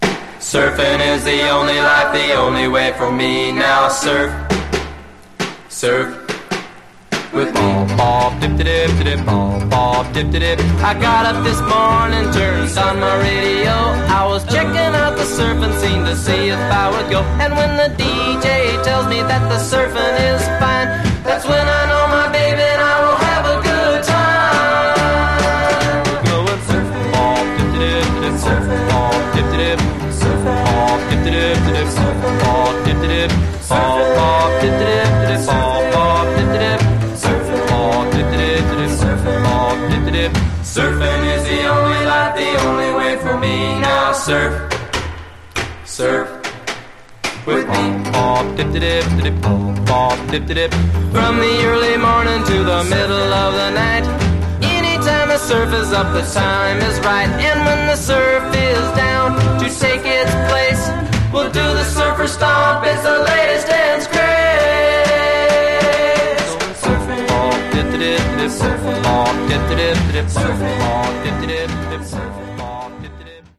Genre: Surf/Cars